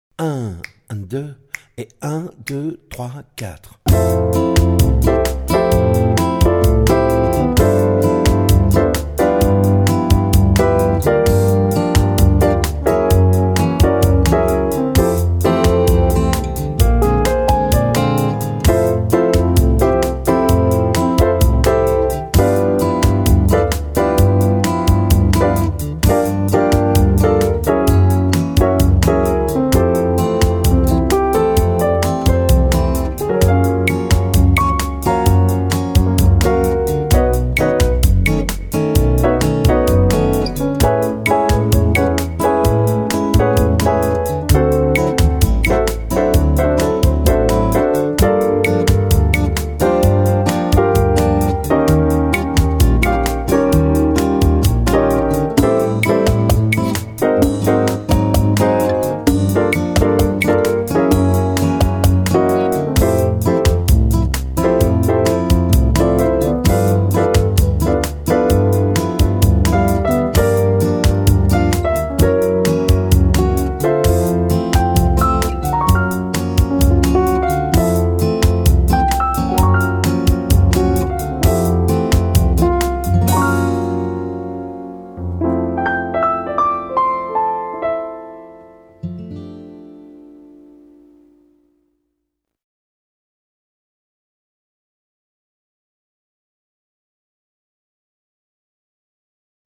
version simplifiée pour saxophone alto et ténor.
version avancée pour saxophone alto et ténor.